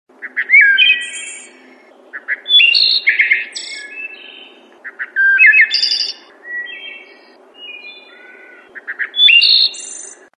알림음(효과음) + 벨소리
알림음 8_새소리.mp3